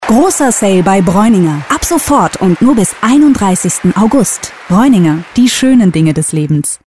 Carolina Vera (Berlin) spricht Vera Carolina Vera ist eine bekannte Schauspielerin und Synchronsprecherin.